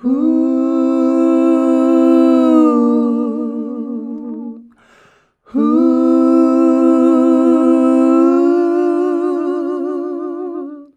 HUUUHUH.wav